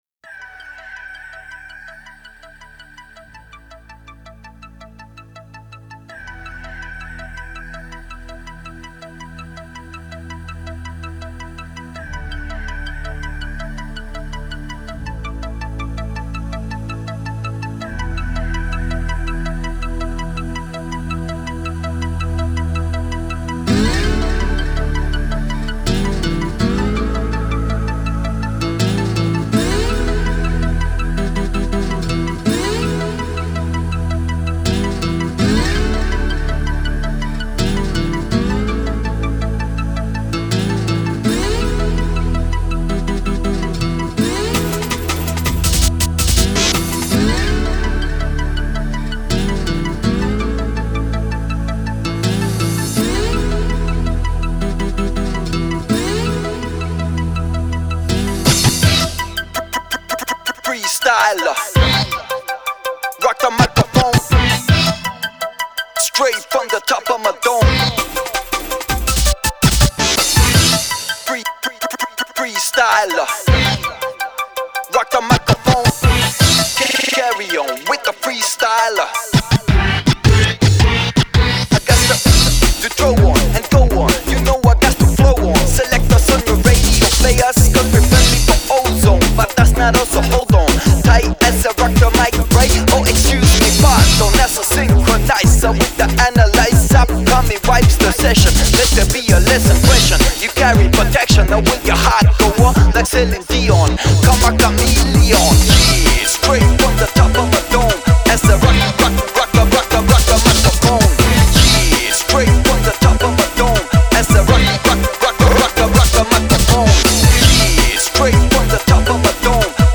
Жанр: хип-хоп-реп